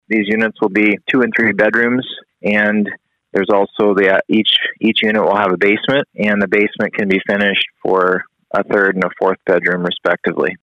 Kooiker describes the twelve proposed housing units.